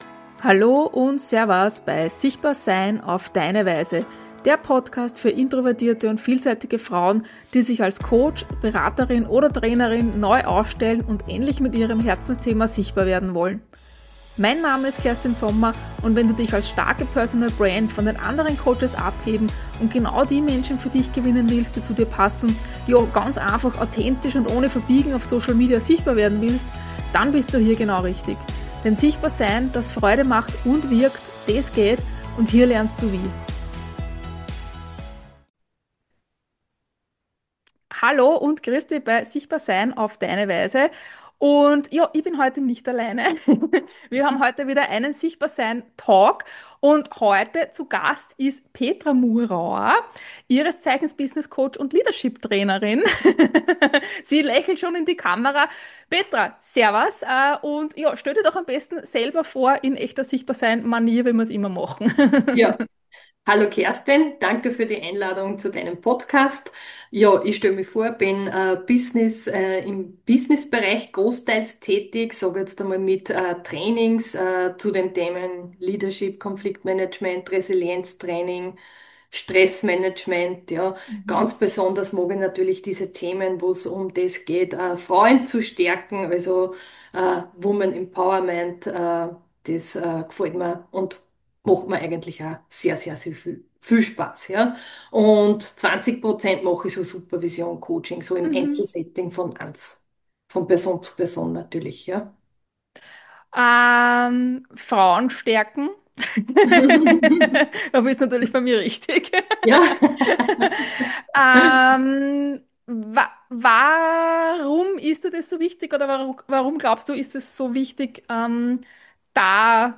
Ein spannendes Interview mit vielen Erkenntnissen und der Frage, braucht die eigene Vision auch das richtige Timing?